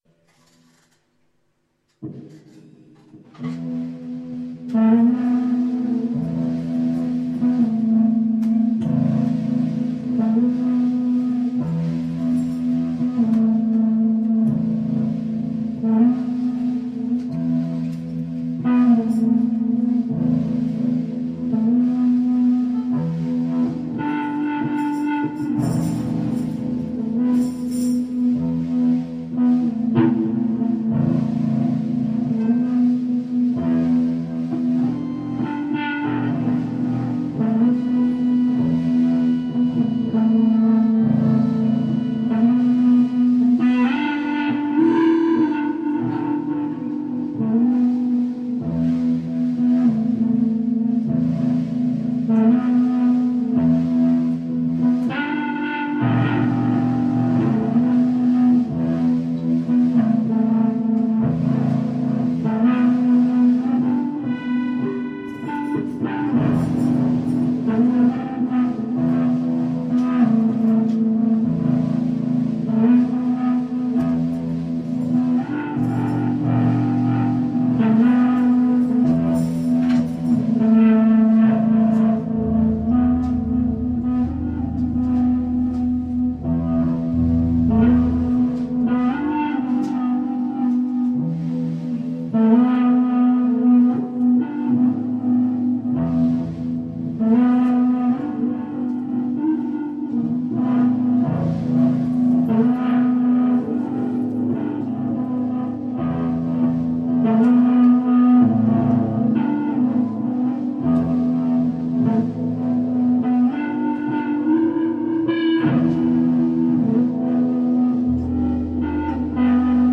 Aus diesem stundenlangen Jamsessions stammen diese und auch weitere Schnipsel. hier mal zwei Beispiele mit sehr ähnlichem Aufnahmepotential. your_browser_is_not_able_to_play_this_audio your_browser_is_not_able_to_play_this_audio